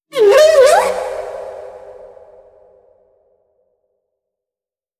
khloCritter_Male06-Verb.wav